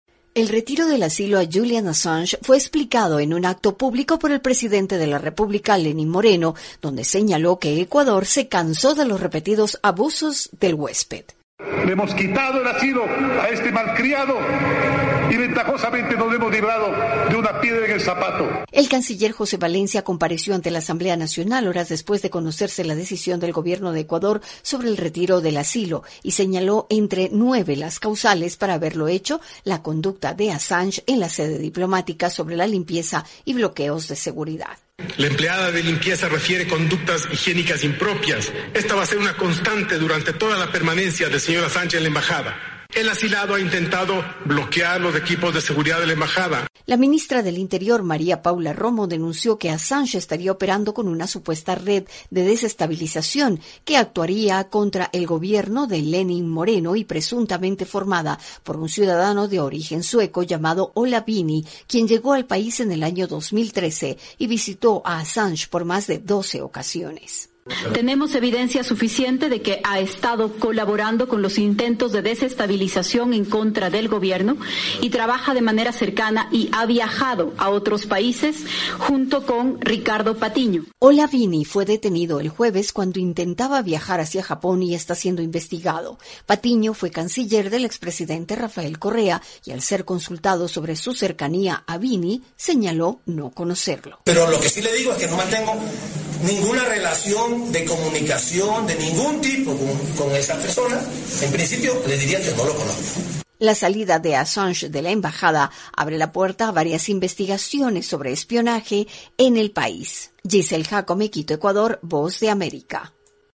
VOA: Informe desde Ecuador